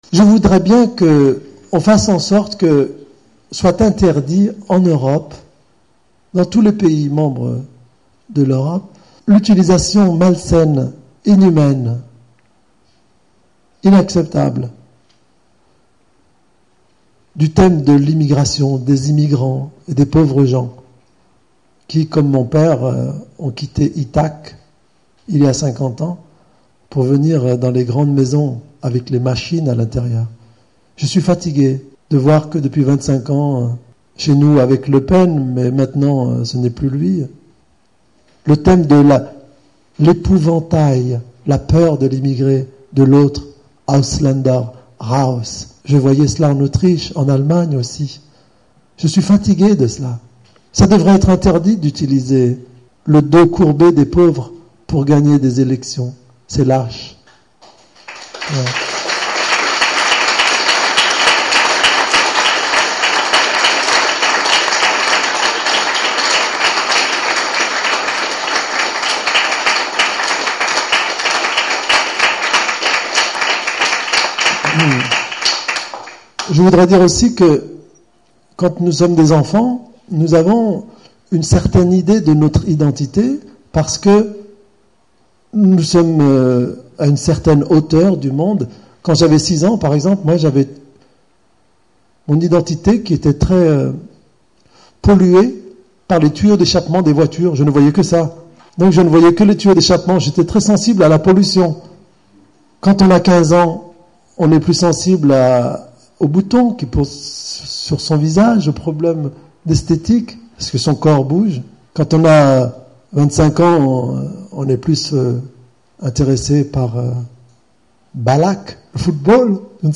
Azouz Begag im Institut français de Stuttgart